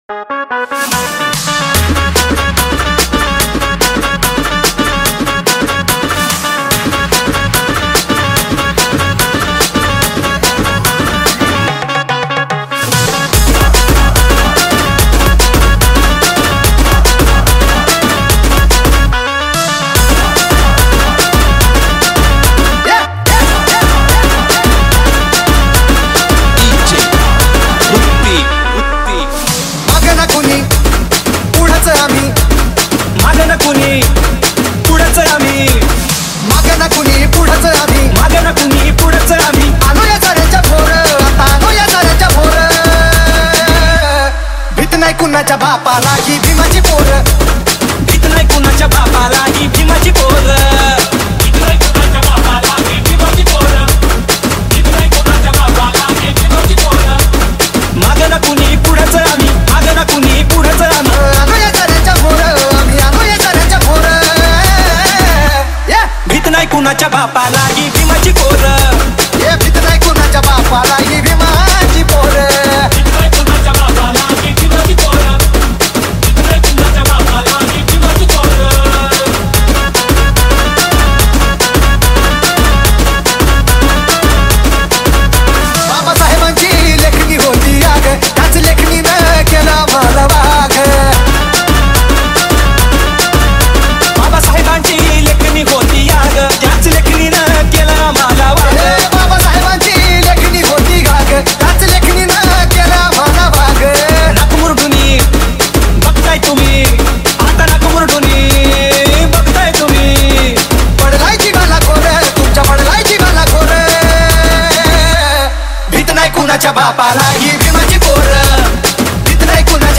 • Category: Marathi Djs Remix